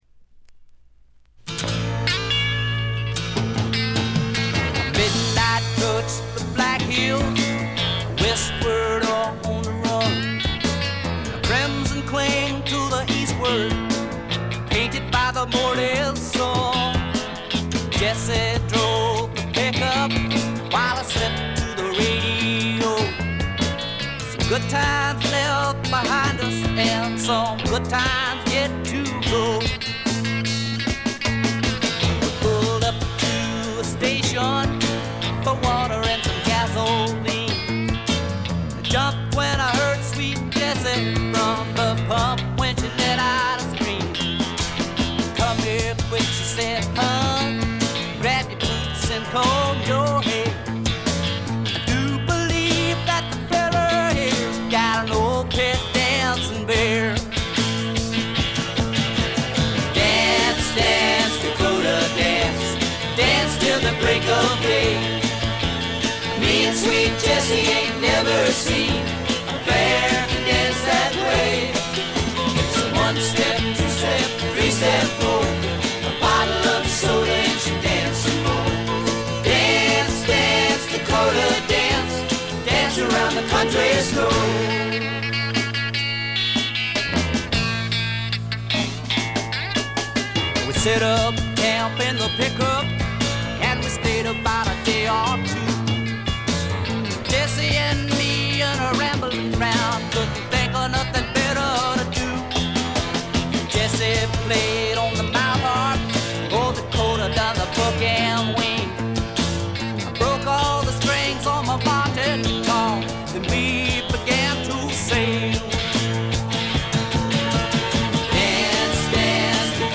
ところどころで軽微なチリプチ。散発的なプツ音少し。
試聴曲は現品からの取り込み音源です。